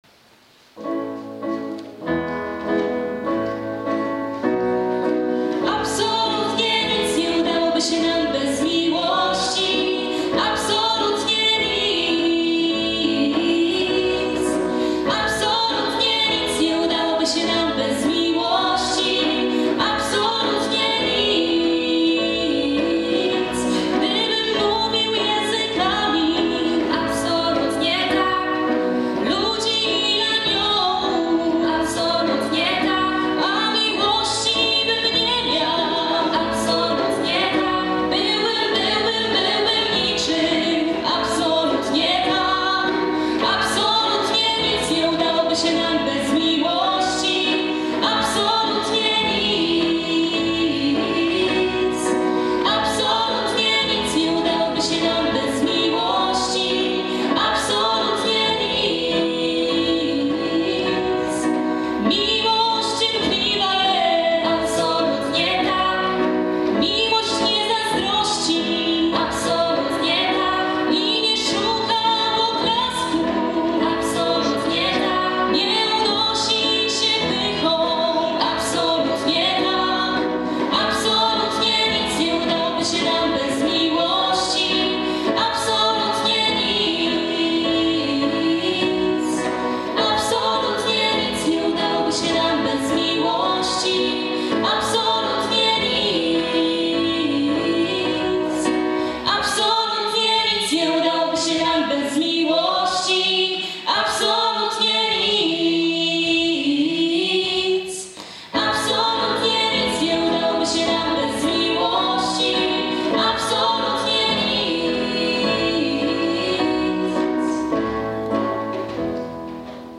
Spotkanie wielkanocne Powiatu Szydłowieckiego
W odświętny nastrój zebranych wprowadził zespół Vivo z gminy Mirów. Ta siedmioosobowa grupa studentów i maturzystów znakomicie dobranym repertuarem przyczyniła się do podkreślenia wielkanocnego charakteru spotkania.